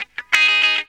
GTR 91 G#M.wav